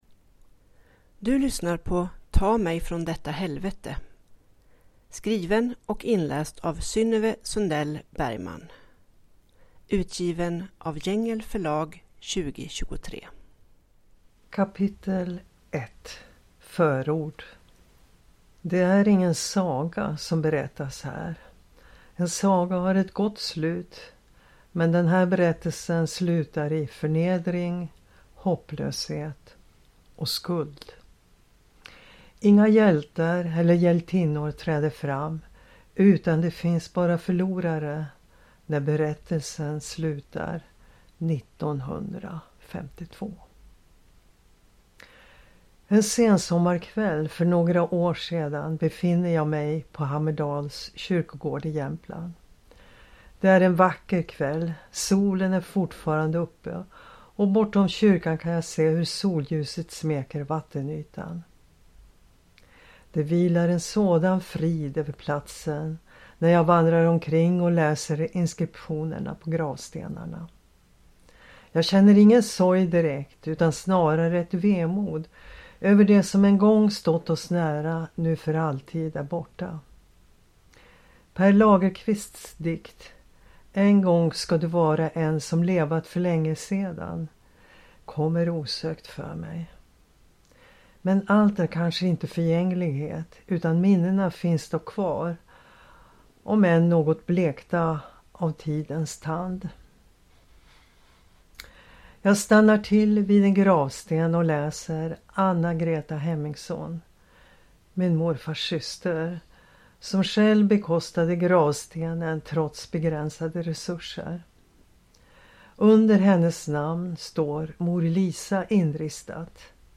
Ta mig ifrån detta helvete (ljudbok) av Synnöve Sundell Bergman